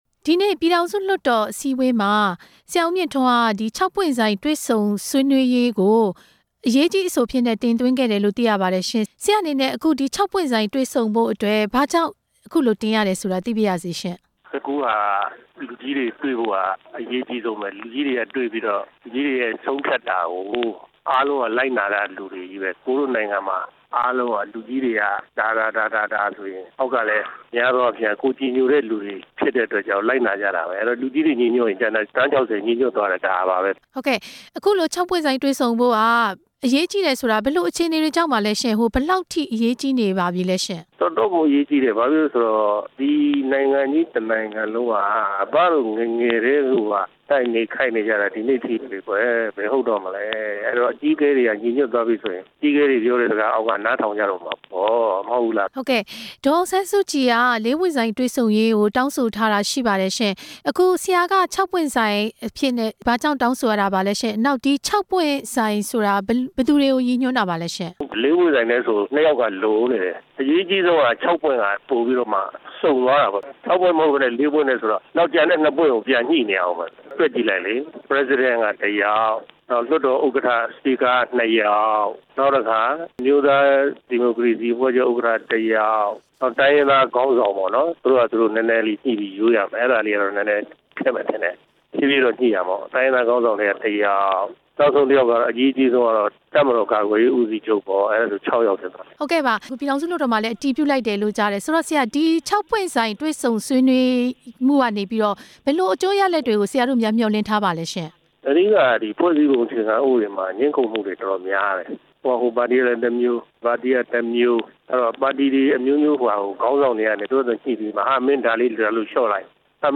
လွှတ်တော်ကိုယ်စားလှယ် ဦးမြင့်ထွန်းကို မေးမြန်းချက်